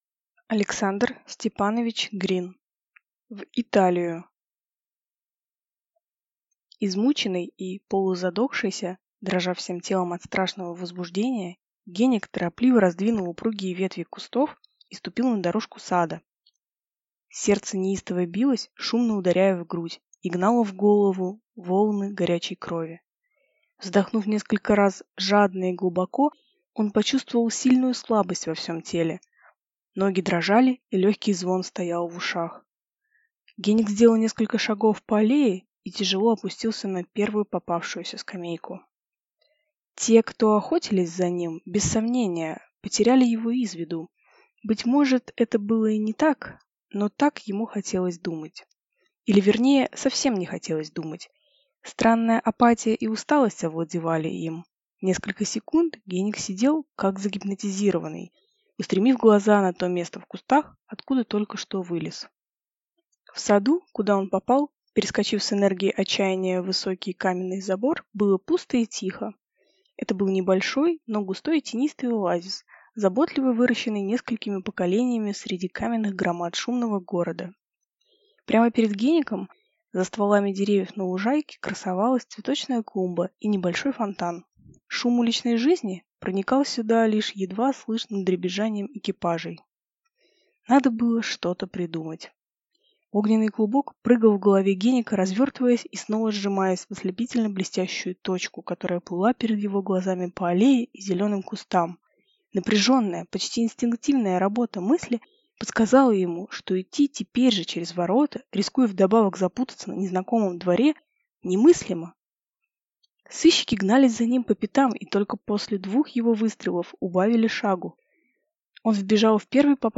Aудиокнига В Италию